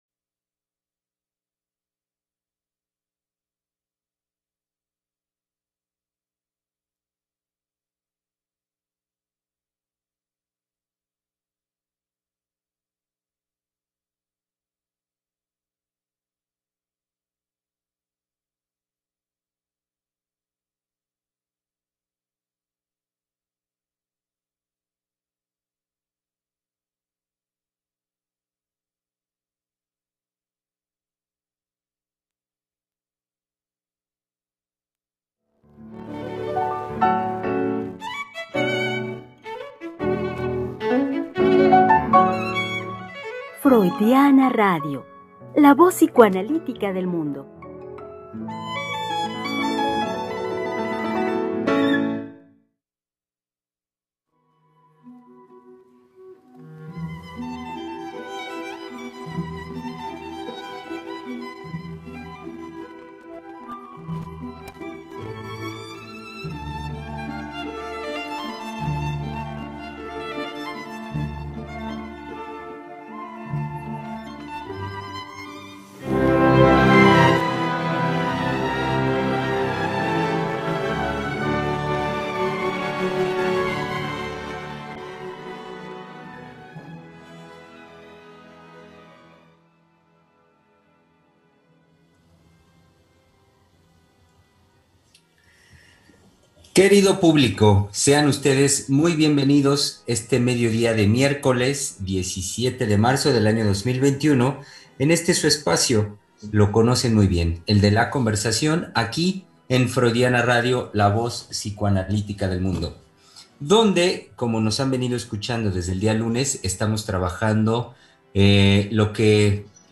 Conversación psicoanalítica